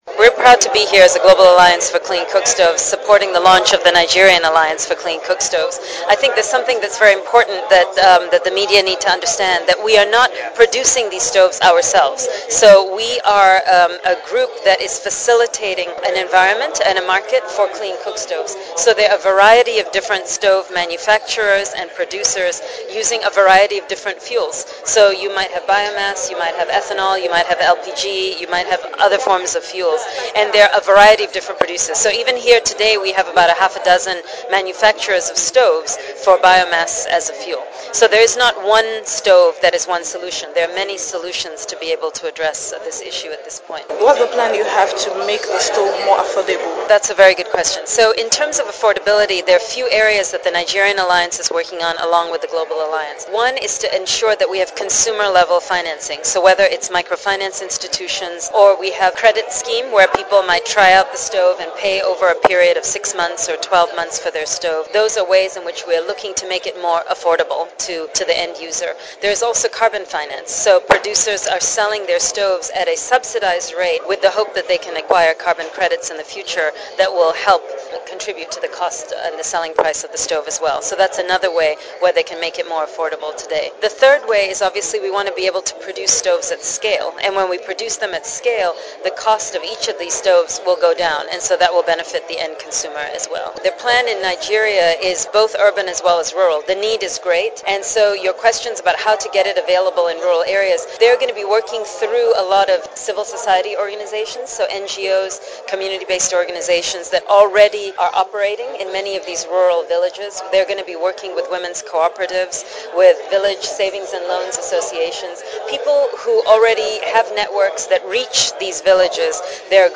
Washington D.C. in this interview during the launch of the stove in Nigeria